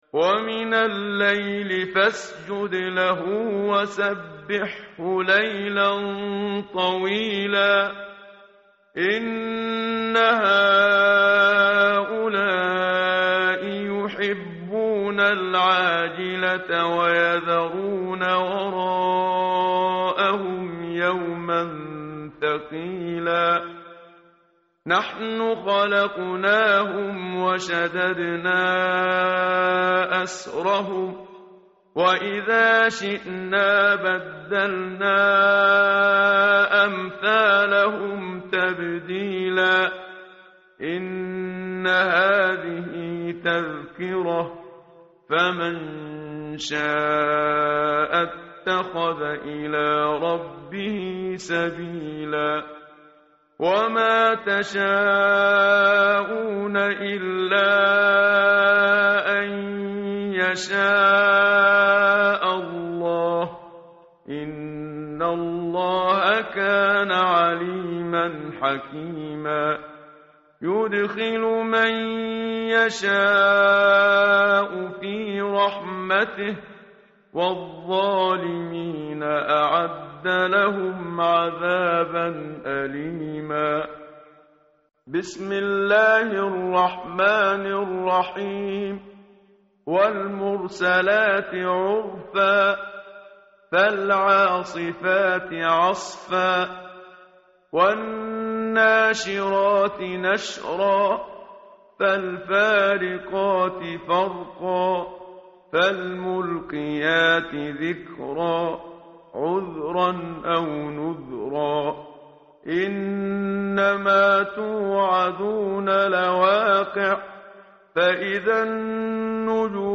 tartil_menshavi_page_580.mp3